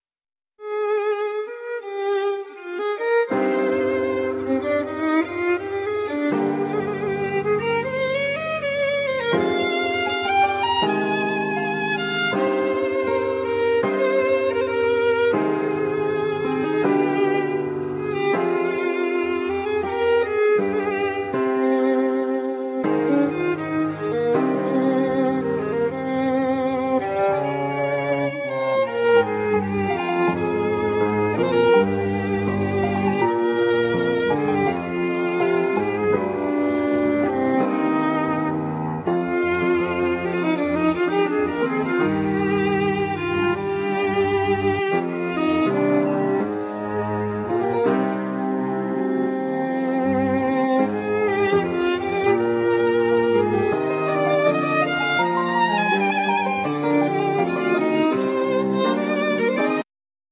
Voice,Keyboards,Dong ruan,Samplers,Percussions
Classic guitar
Trumpet,Piccolo trumpet
Timpanos,Snare,Drum,Cymbals,Gong
Chinese voice